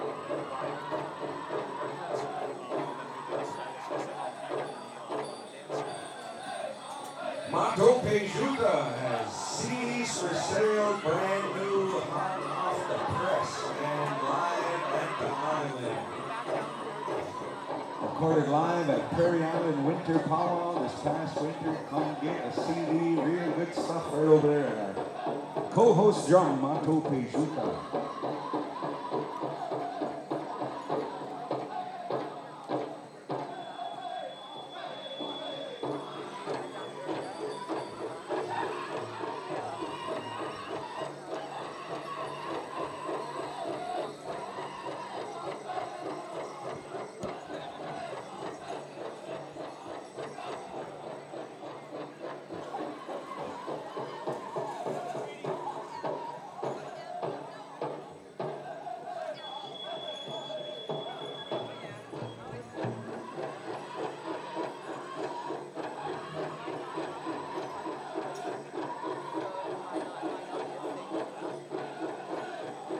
Menominee Homecoming Powwow August 2023